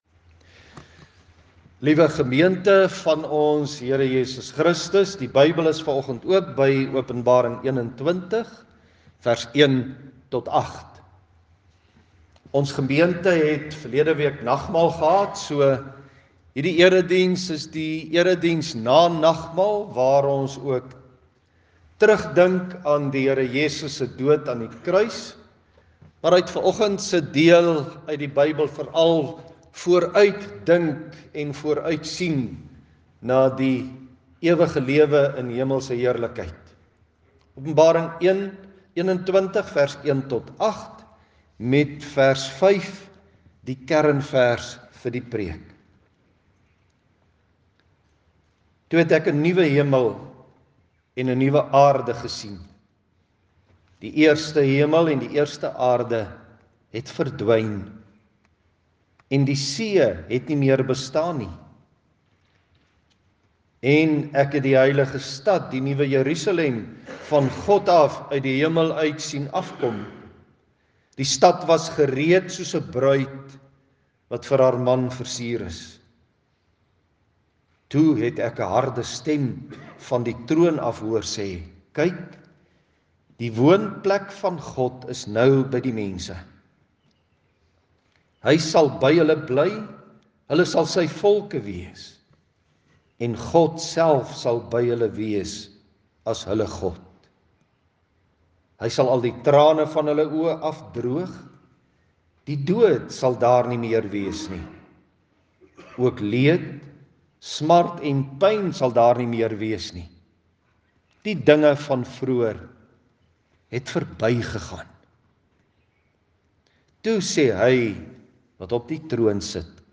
Klankbaan Luister na die preek.